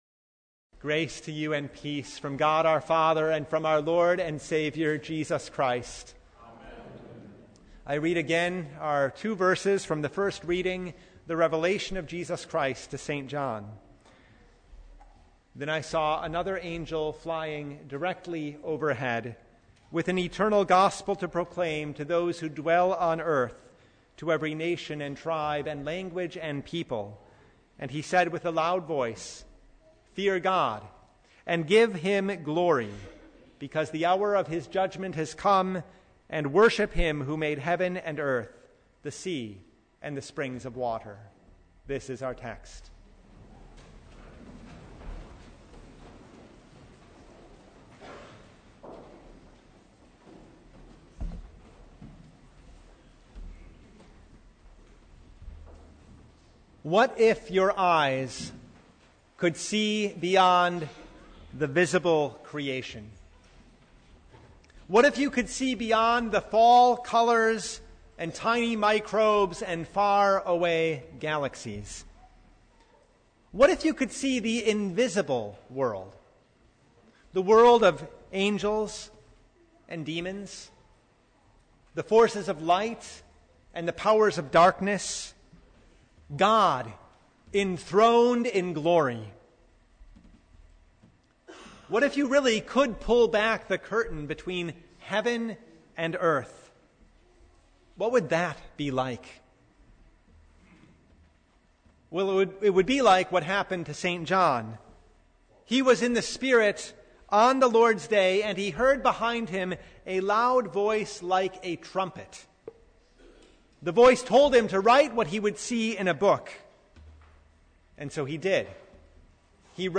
Revelation 14:6-7 Service Type: The Festival of the Reformation Frightened by things in Revelation?